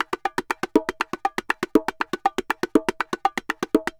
Bongo_Salsa 120_1.wav